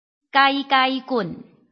臺灣客語拼音學習網-客語聽讀拼-南四縣腔-開尾韻